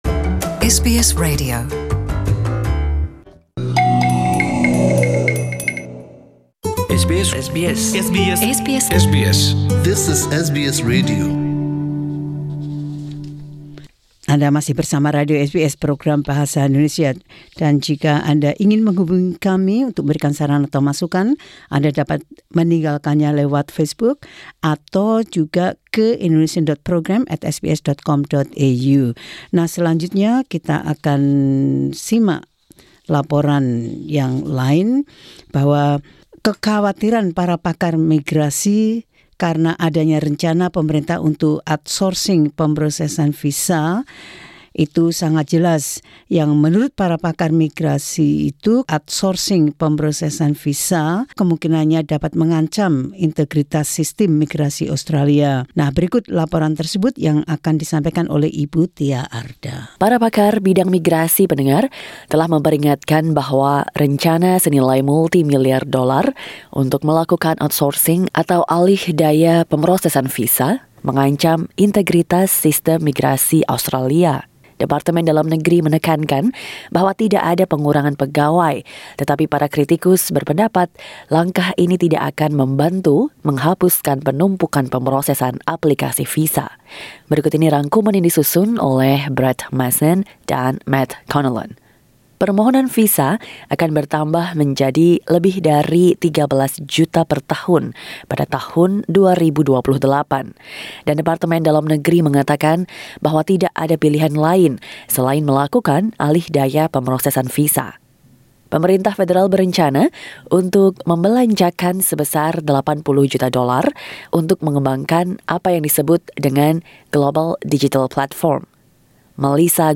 SBS Radio News in Indonesian 6 Nov 2019.